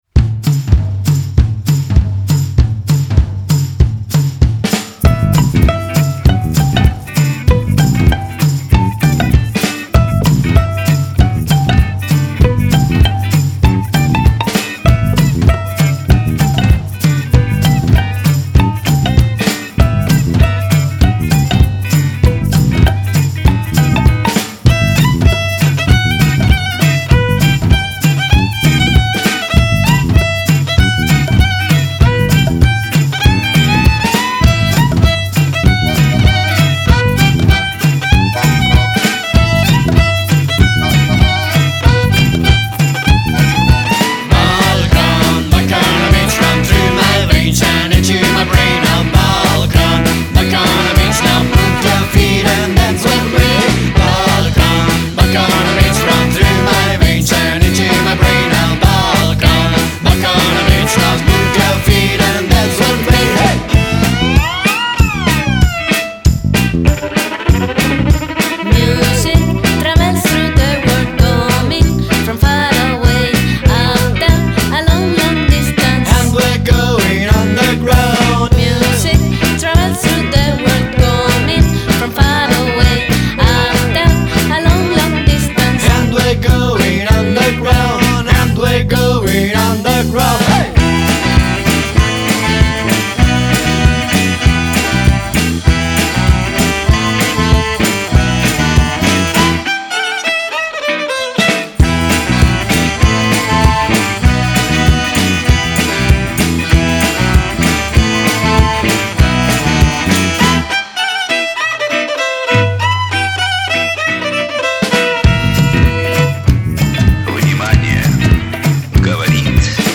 Genre: Folk-Rock, Gypsy-Punk